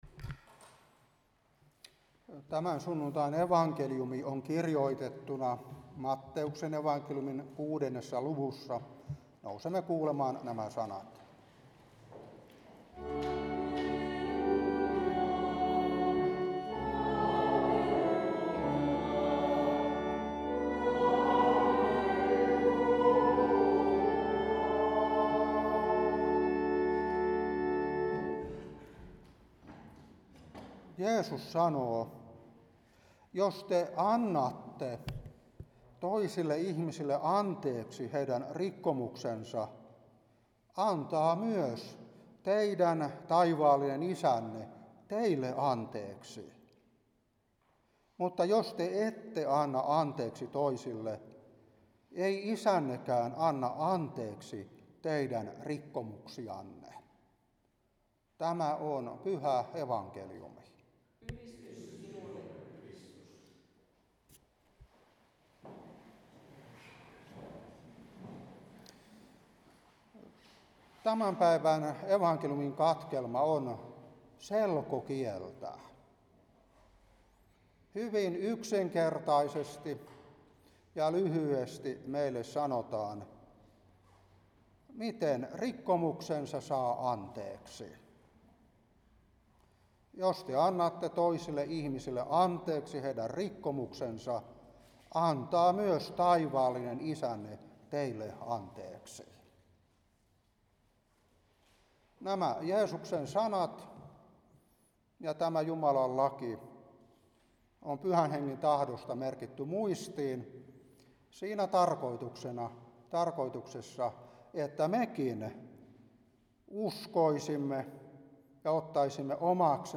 Saarna 2024-10.